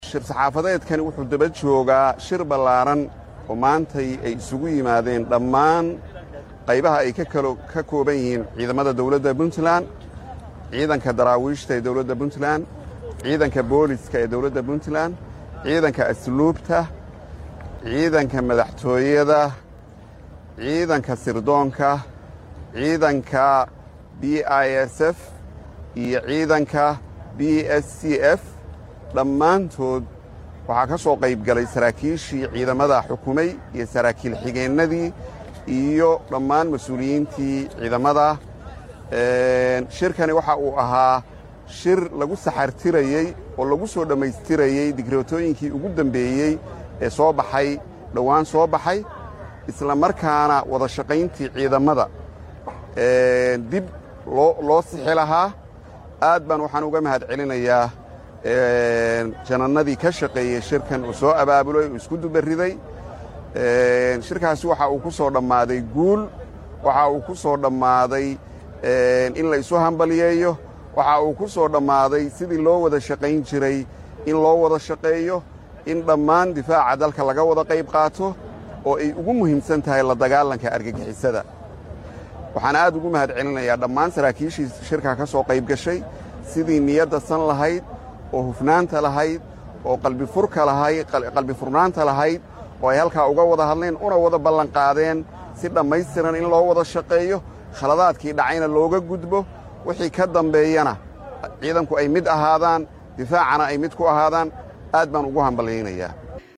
Mar uu warbaahinta la hadlay ayuu yiri wasiirka wasaaradda amniga ee Puntland.